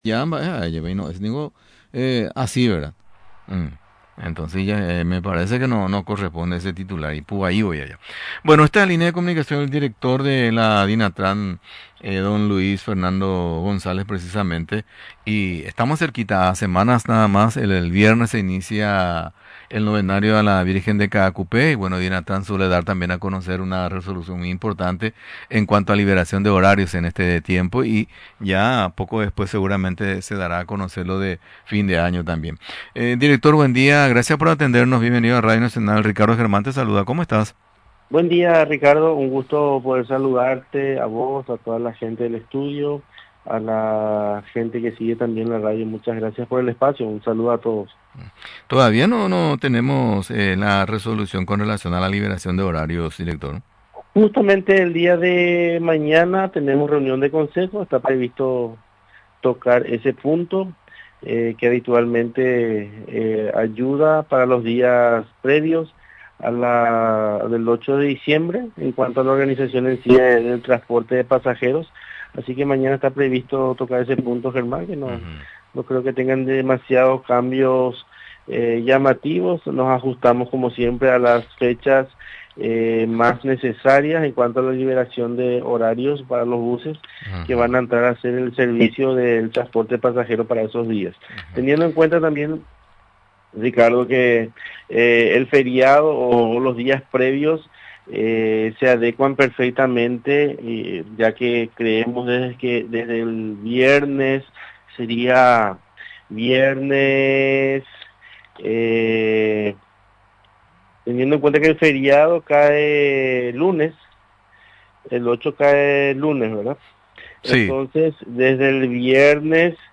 Durante la entrevista en Radio Nacional del Paraguay, recordó las ediciones anteriores de la citada celebración que se realiza en Caacupé, sobre el horario y el costo de pasajes para llegar a la capital espiritual.